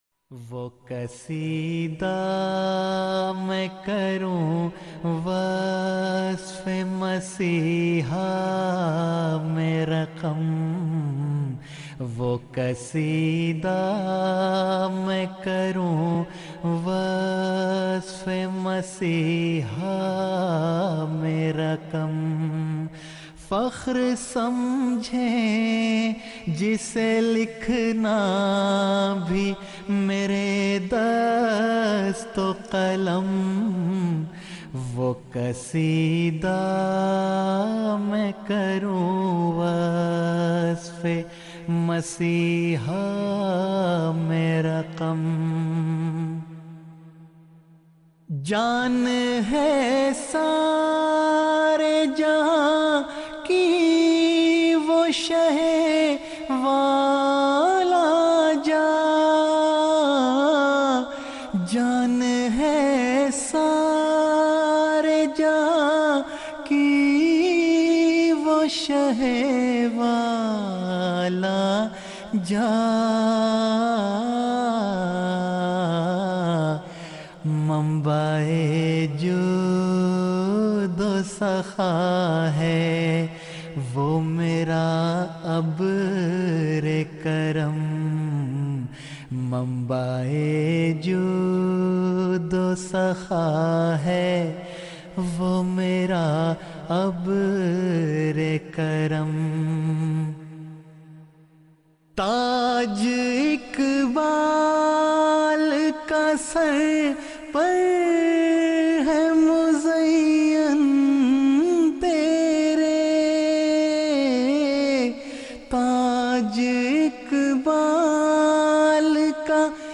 Urdu Poems
Voice: Member Lajna Ima`illah
(Jalsa UK)